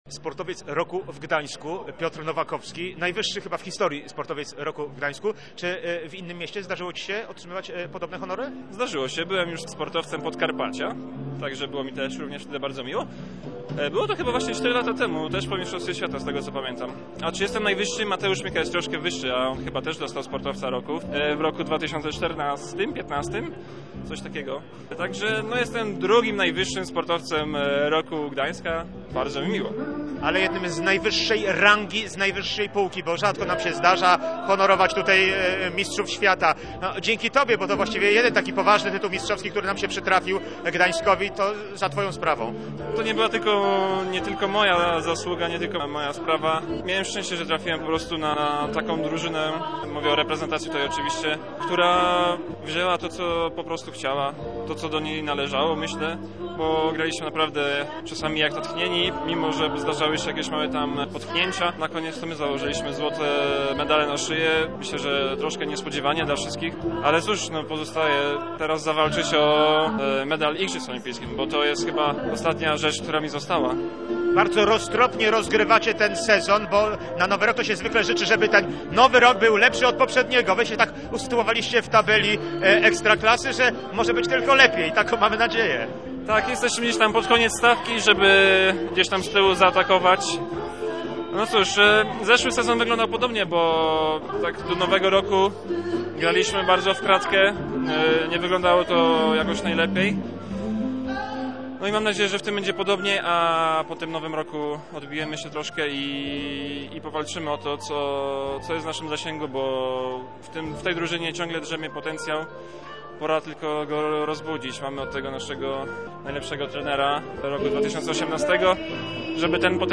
Posłuchaj rozmowy z Piotrem Nowakowskim: /audio/dok2/nowakowskizbis.mp3 ANASTASI (TREFL) i STOKOWIEC (LECHIA) NAJLEPSI Trenerzy roku pracują w Treflu i Lechii.